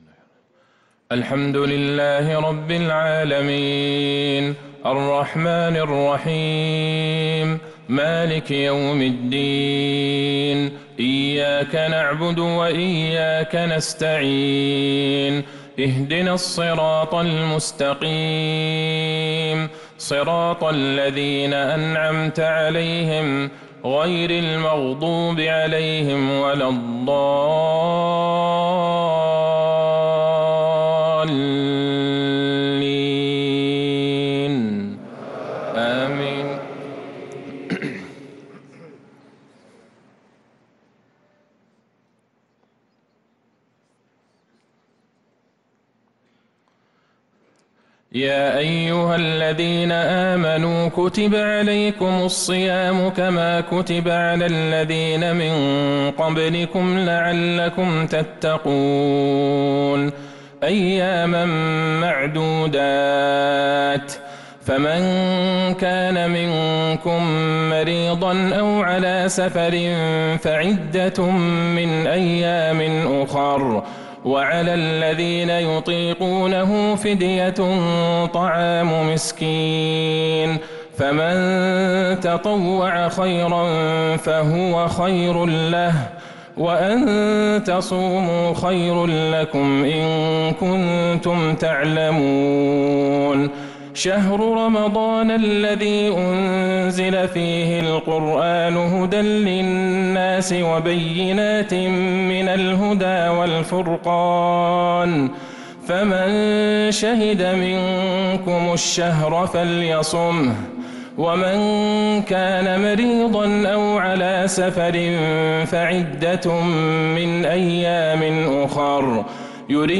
عشاء الأربعاء 14 محرم 1447هـ | من سورة البقرة 183-188 | Isha prayer from Surah Al-Baqarah 9-7-2025 > 1447 🕌 > الفروض - تلاوات الحرمين